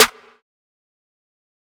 TC SNARE 05.wav